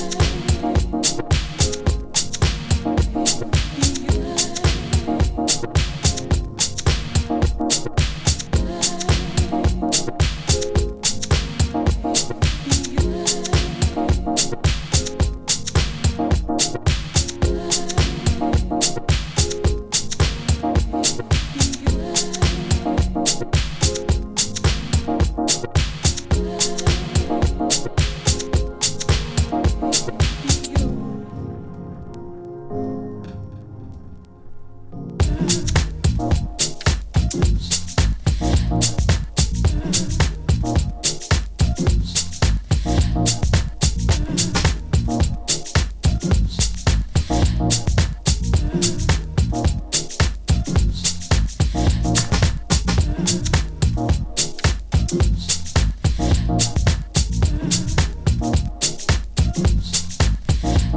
温かみのあるシンセと、声ネタがハマった心地負いBeat Downトラック